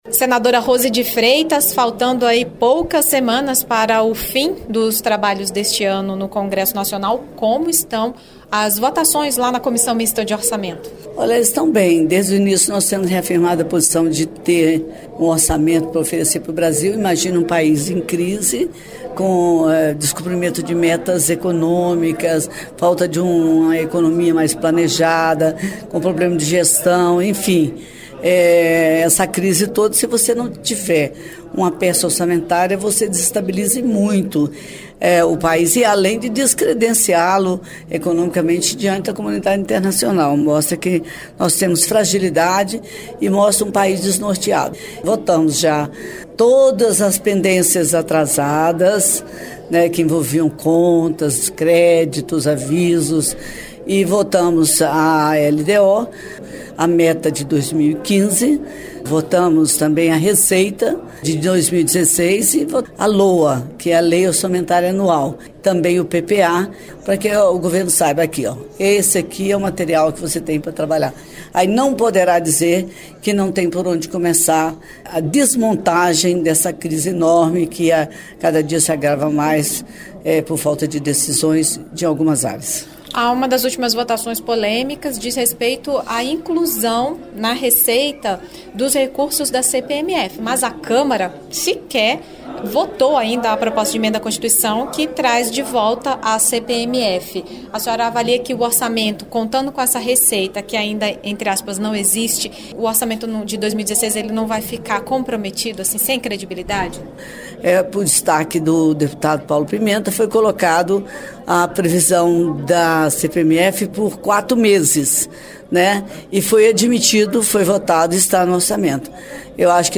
Senado em Revista: Entrevistas - 04/12/2015